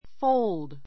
fóuld ふォ ウ るド